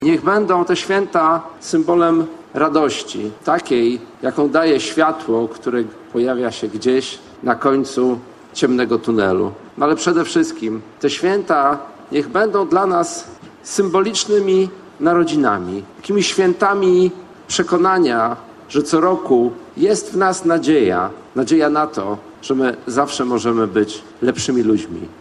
Niech nadchodzące święta będą symbolem ciepła - tego życzył poznaniakom prezydent miasta Ryszard Grobelny. Życzenia złożył na zakończenie trwającego od początku grudnia na Starym Rynku, Betlejem Poznańskiego.